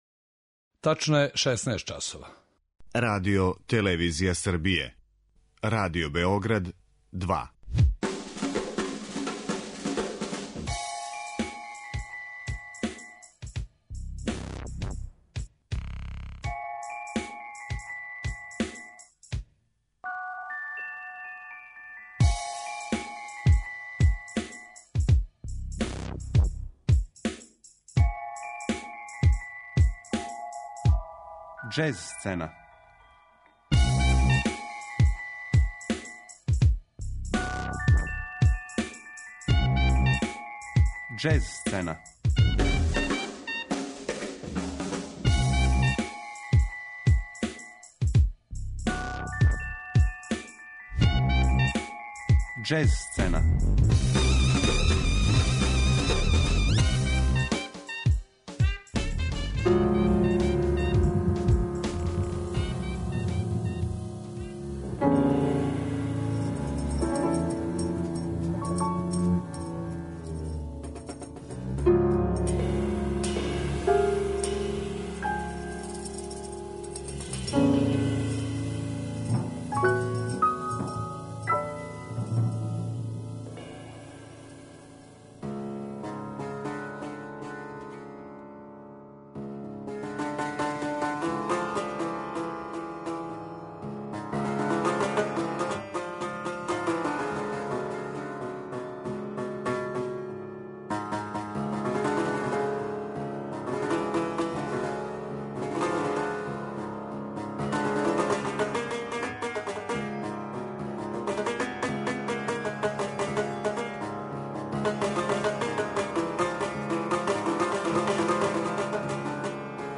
контрабас
бубњеви